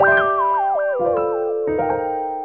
gameover_sound.mp3